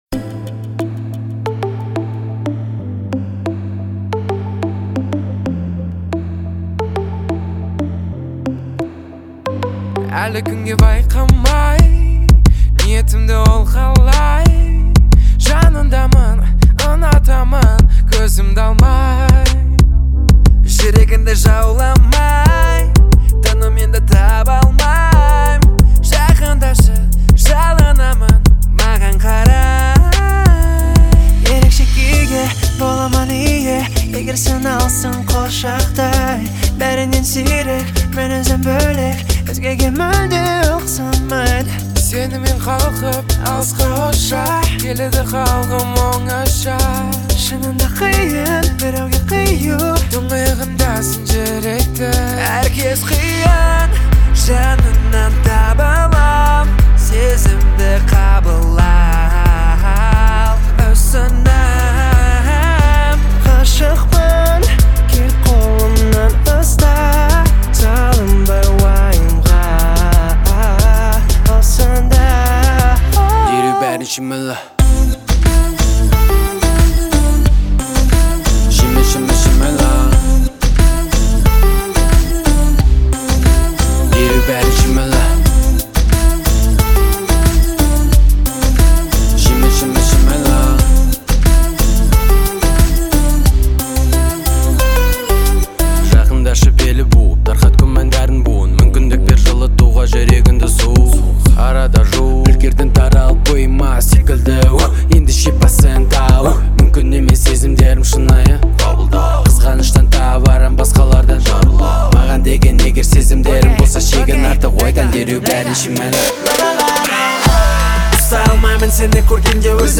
это яркий трек в жанре инди-поп